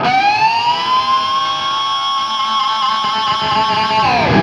DIVEBOMB18-L.wav